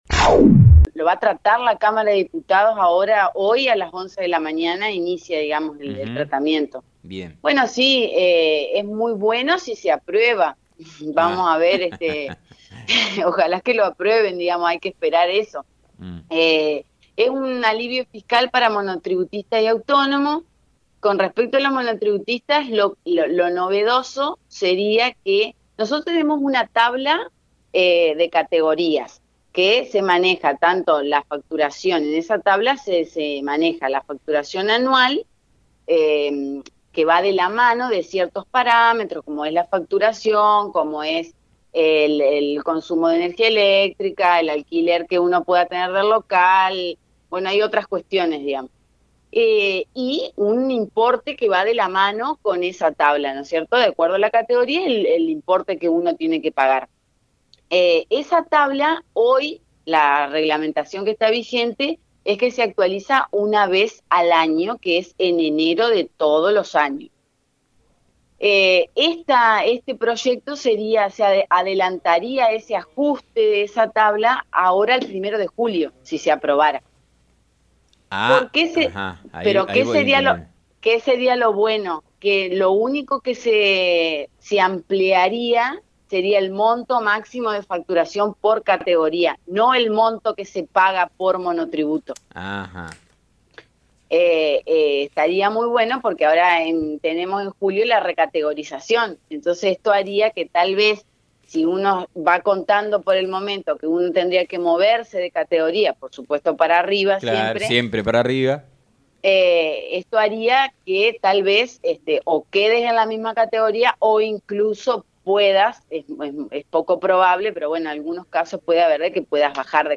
dialogó con FM 90.3 y contó algunos detalles de a quienes llegaría esta medida y cuáles son las razones por las que se busca la aprobación de la ley